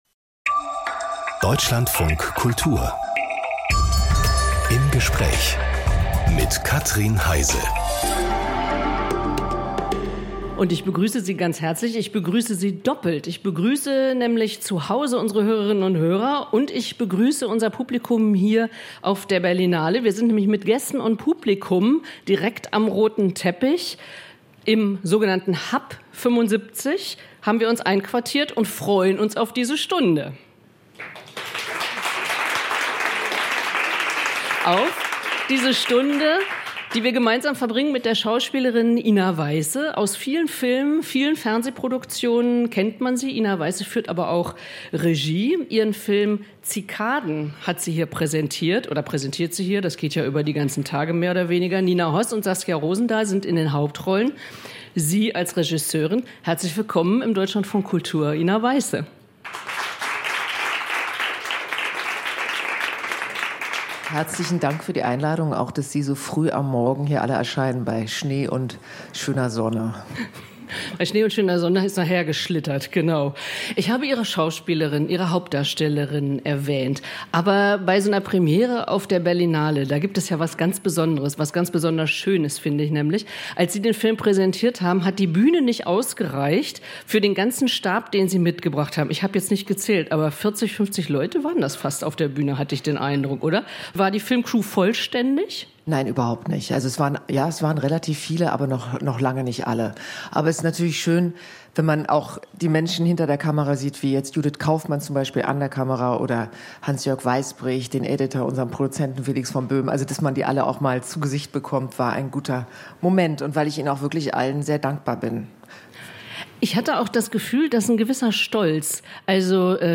Aus dem Podcast Im Gespräch Podcast abonnieren Podcast hören Podcast Im Gespräch Eine ganze Stunde widmen wir einer Person.